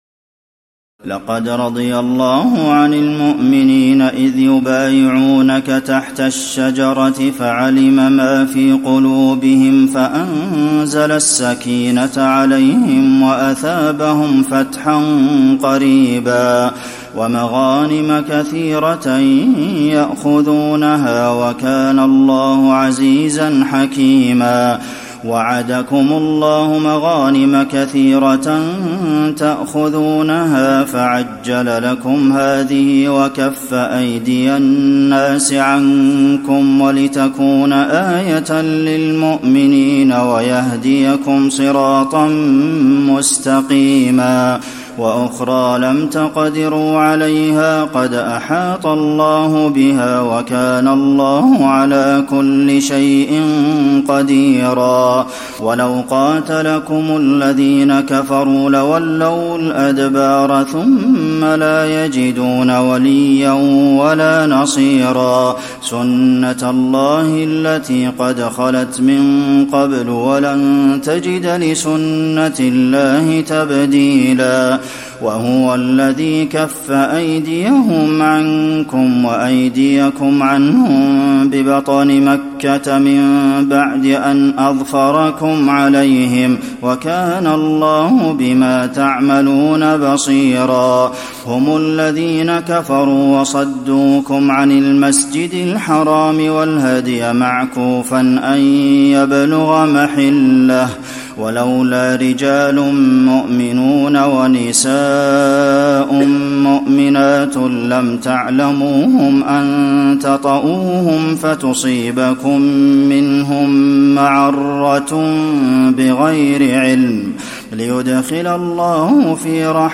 تراويح ليلة 25 رمضان 1435هـ من سور الفتح (18-29) الحجرات و ق و الذاريات (1-23) Taraweeh 25 st night Ramadan 1435H from Surah Al-Fath and Al-Hujuraat and Qaaf and Adh-Dhaariyat > تراويح الحرم النبوي عام 1435 🕌 > التراويح - تلاوات الحرمين